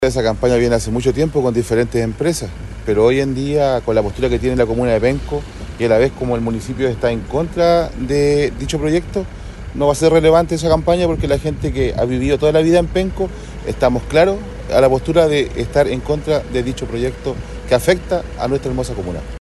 Finalmente, la concejala Leslie Valenzuela subrayó los riesgos ecológicos de la explotación minera propuesta y reiteró que “la comunidad siempre ha dicho que no a la minera”.